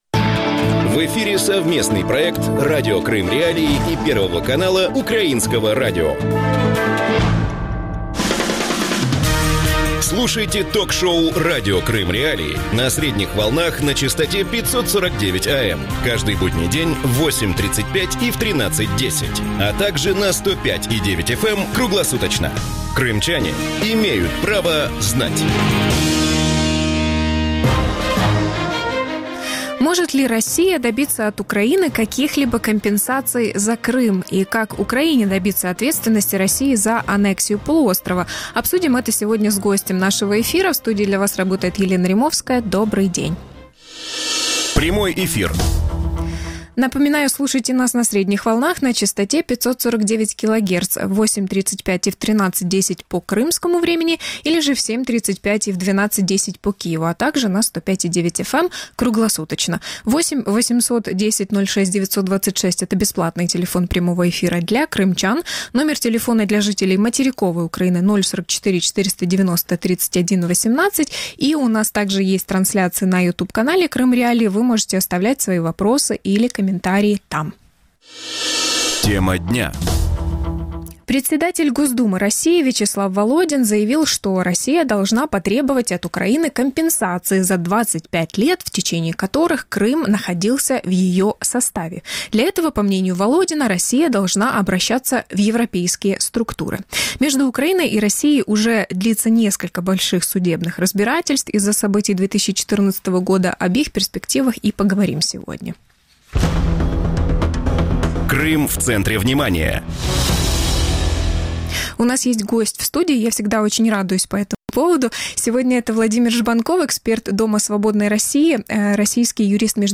Гость студии